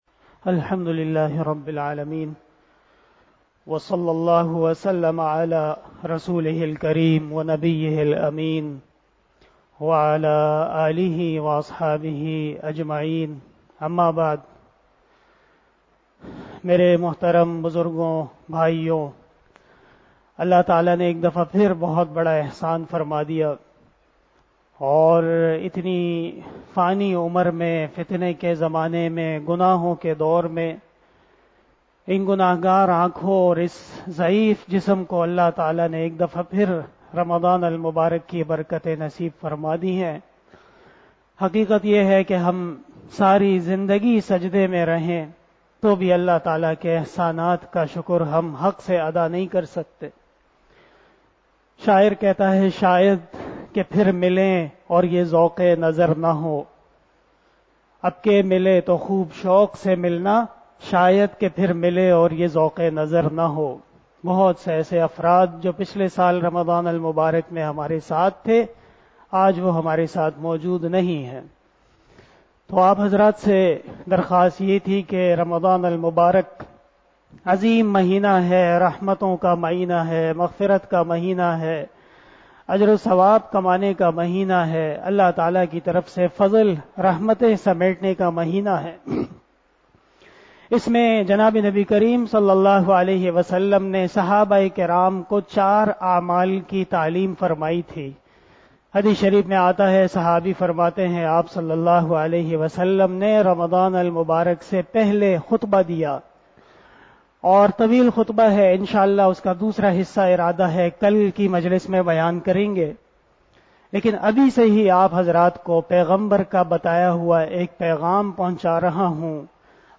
022 AfterTraveeh Namaz Bayan 02 April 2022 ( 01 Ramadan 1443HJ) Saturday